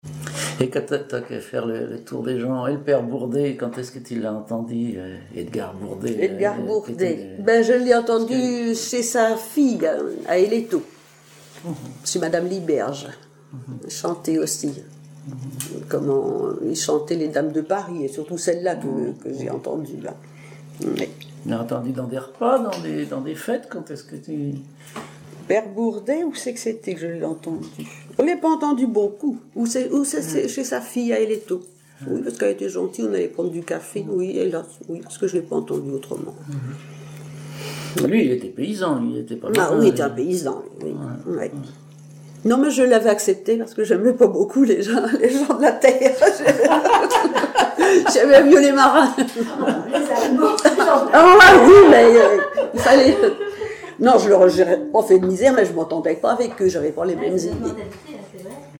Localisation Saint-Pierre-en-Port
Catégorie Témoignage